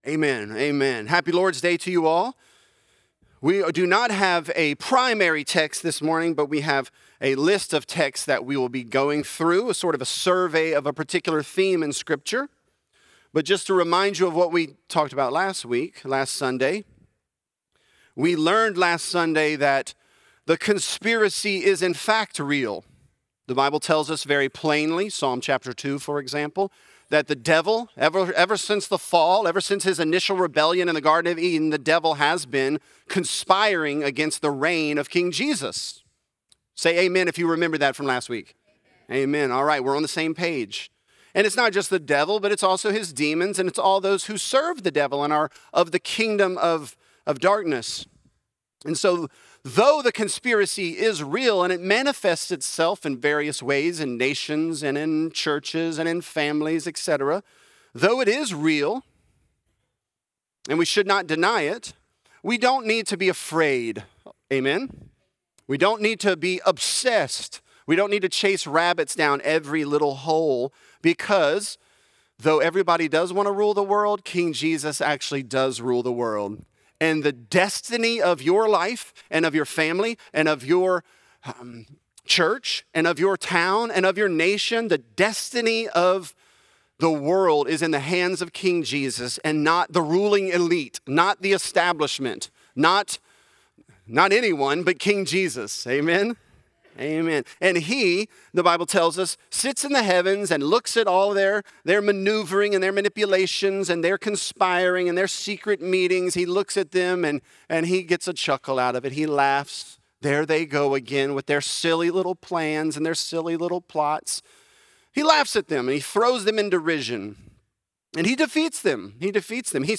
Conspiracy: The Will Of The People | Lafayette - Sermon (John 18)